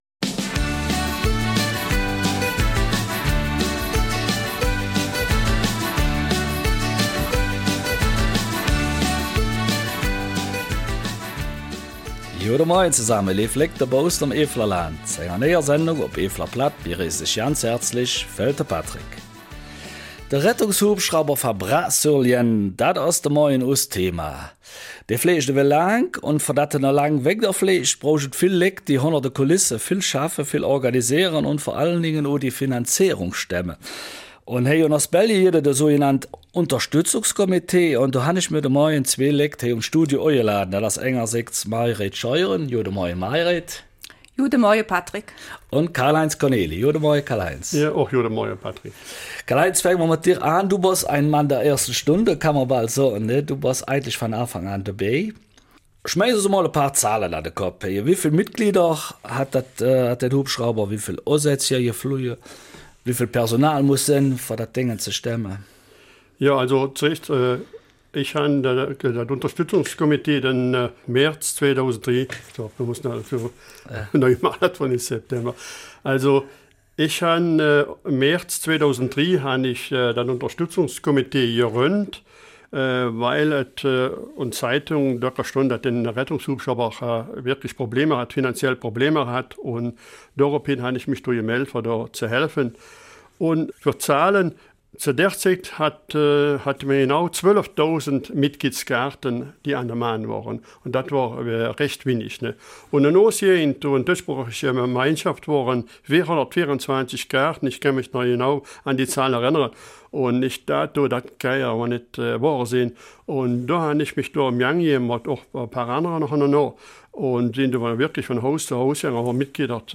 Eifeler Mundart - 9. Oktober